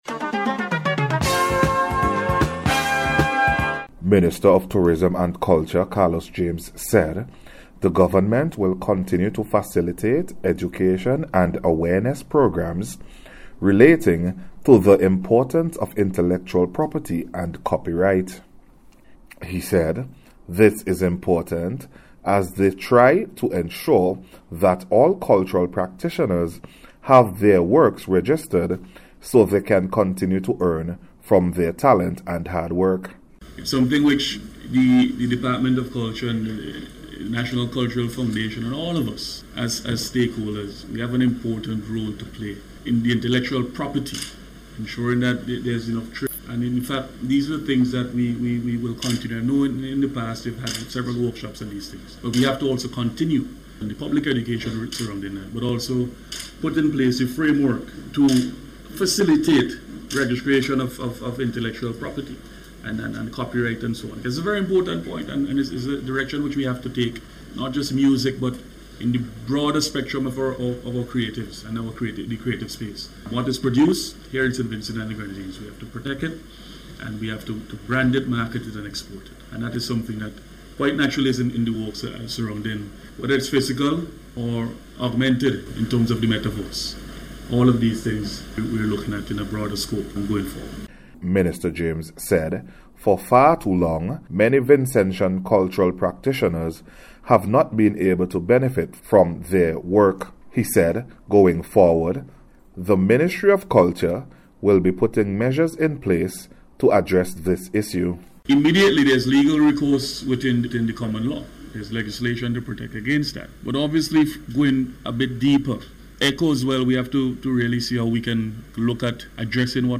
Minister James made the announcement during a media briefing hosted by the Carnival Development Corporation (CDC) to update the nation on plans for Vincy Mas 2022.